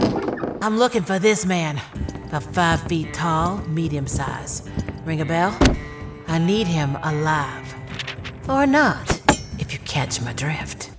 texan us | natural